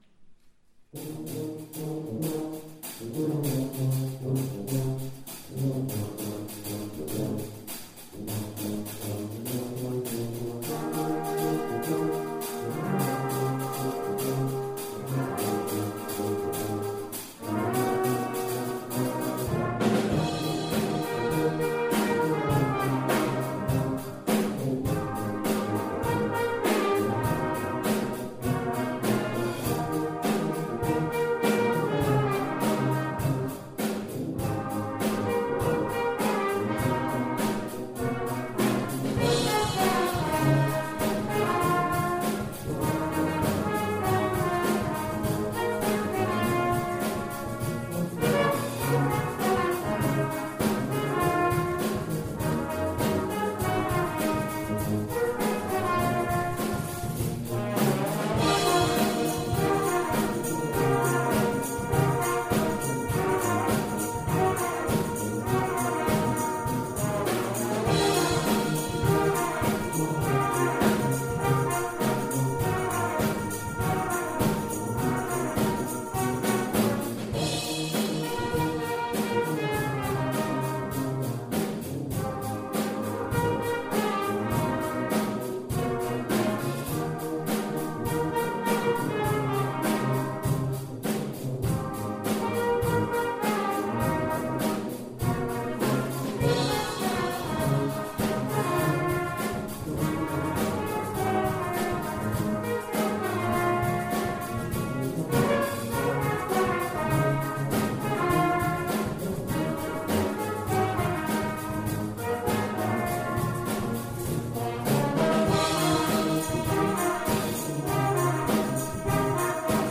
A Lovely Day - Brass Ensemble